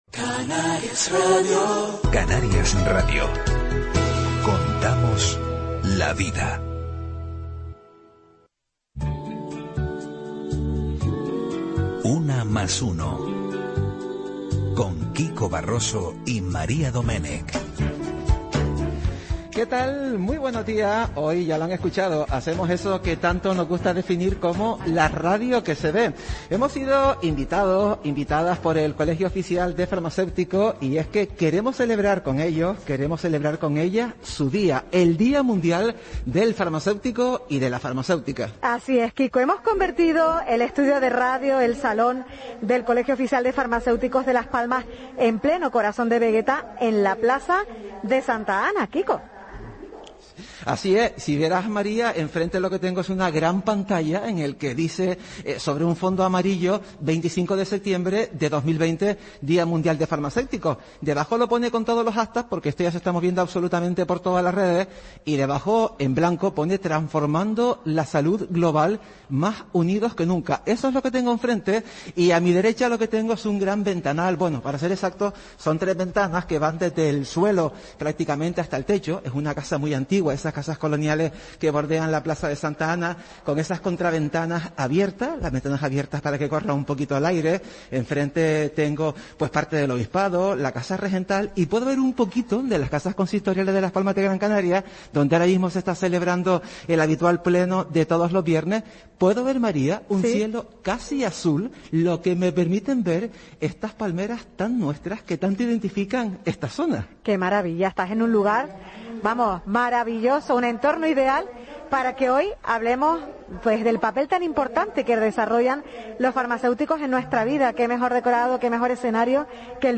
Si quieres puedes volver a escuchar completo el programa Una+Una que se unió al Día Mundial del Farmacéutico desde la sede del COF de Las Palmas en la Plaza de Santa Ana pulsando en el play.
GrabacionRadioAutonomicaUnamasUno_DiaMundialFarmaceutico.mp3